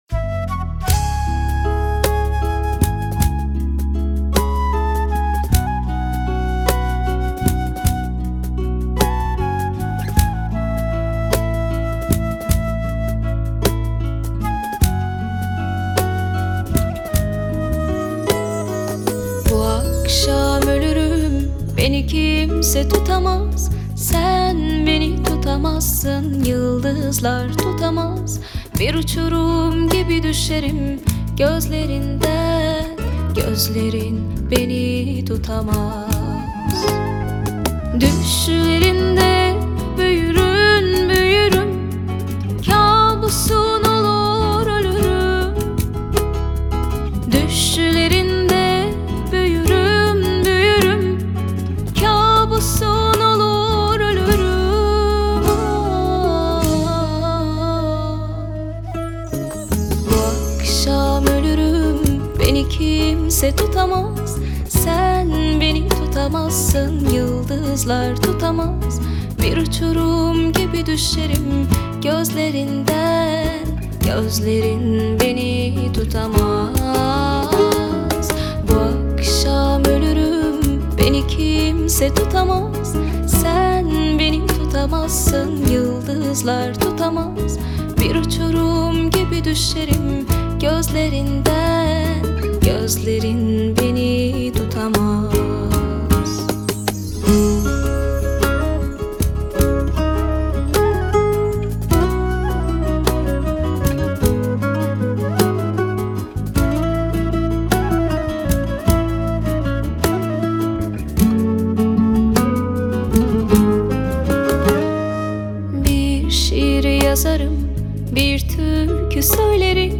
این نسخه دوصدایی با صدای زن و مرد را از دست ندهید!